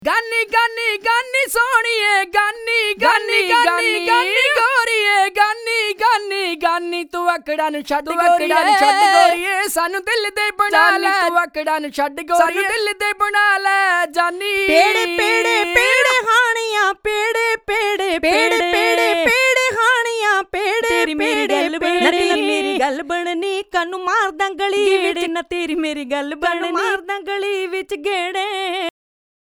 Key F Bpm 94